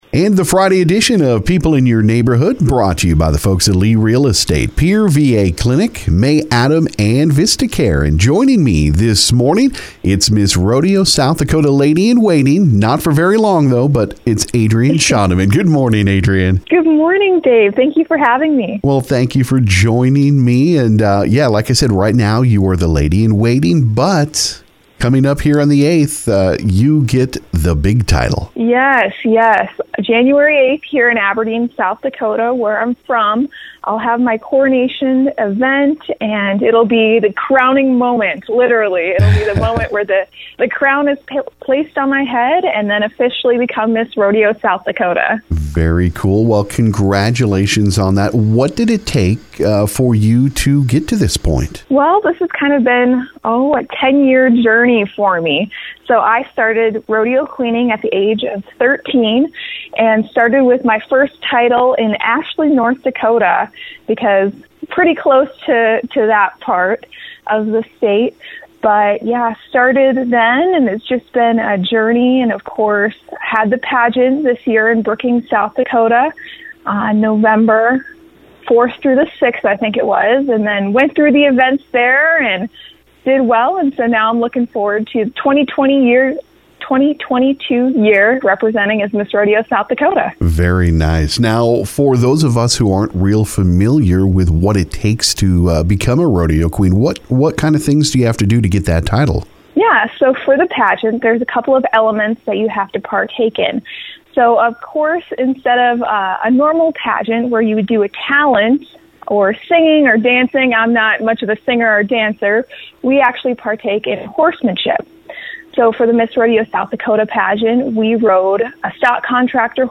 This morning on KGFX